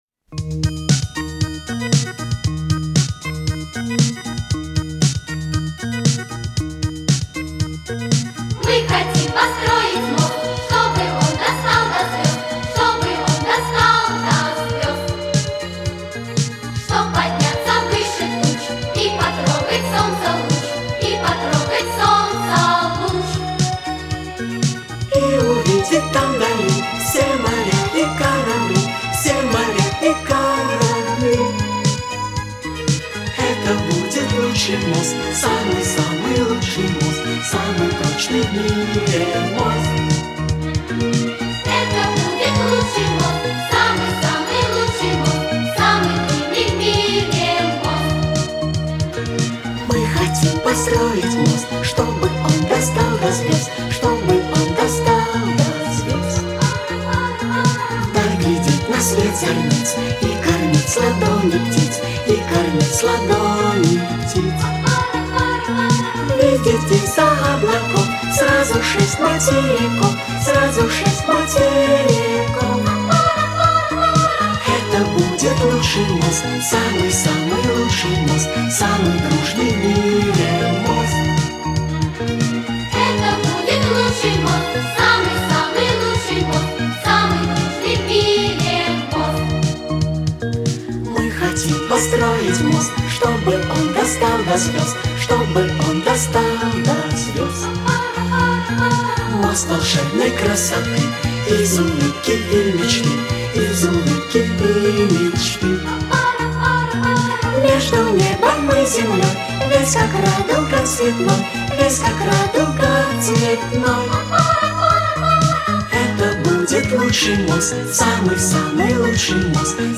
• Качество: Хорошее
• Категория: Детские песни